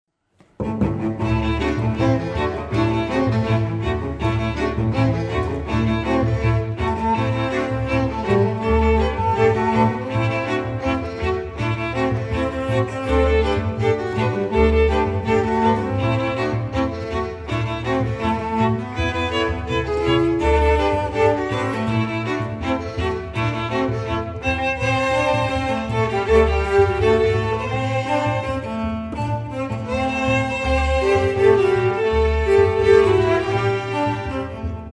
Oldie Musik